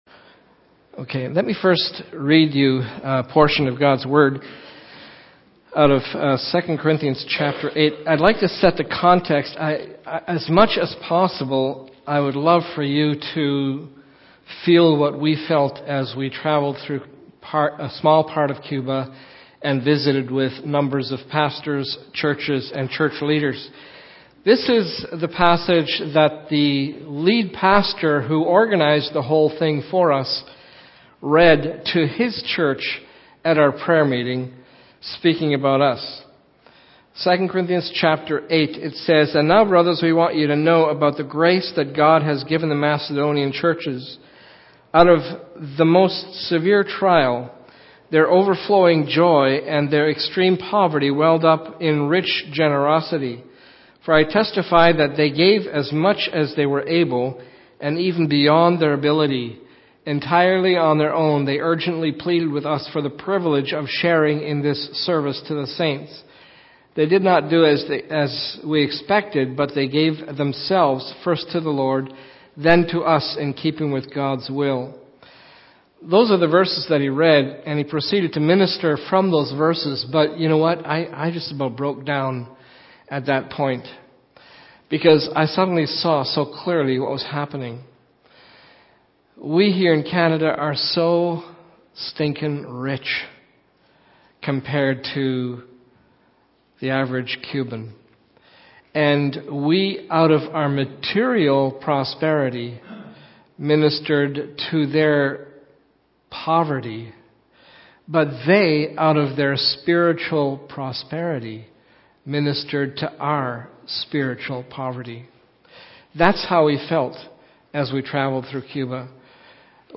This is the presentation and report that the Pastor and his wife gave after their trip to Cuba. The slides cannot be seen obviously, however it is very interesting to hear what God taught them and to find out what we can learn from them recounting their experiences of a country so different then our own.